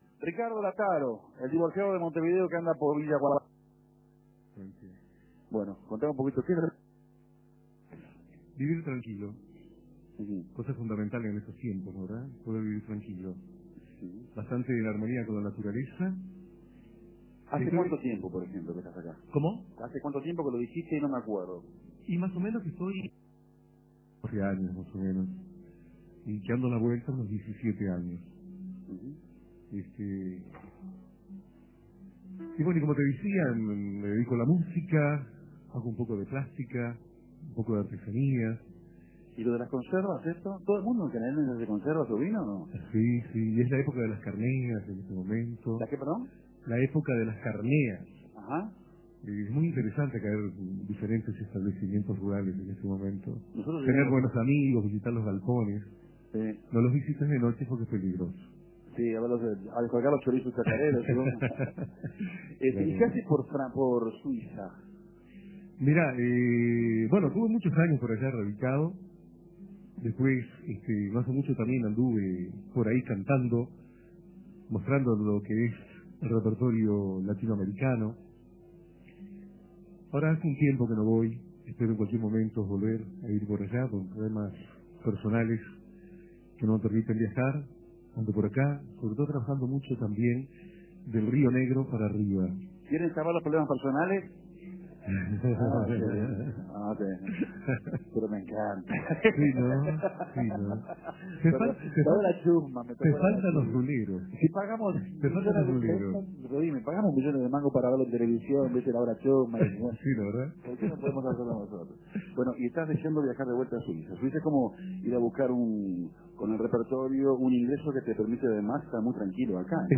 El segundo aporte musical de la noche en Canelones.